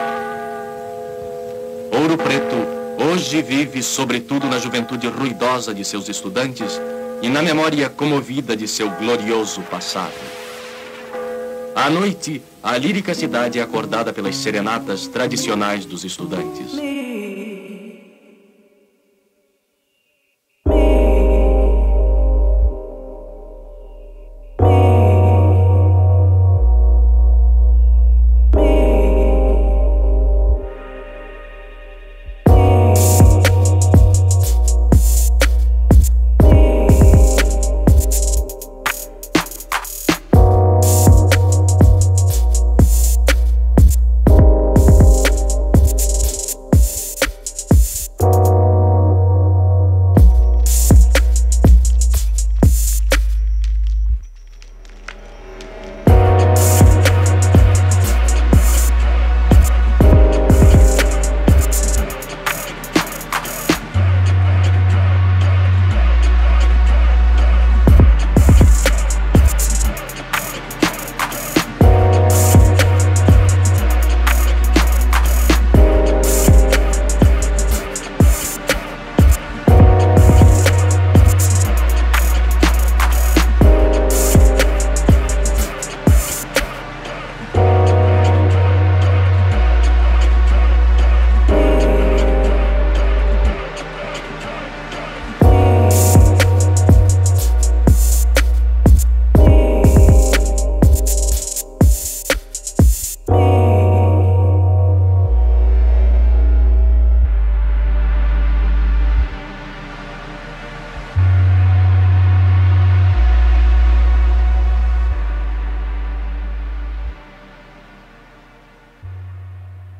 Rap Instrumentals